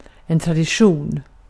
Ääntäminen
US Tuntematon aksentti: IPA : /trəˈdɪʃ.ən/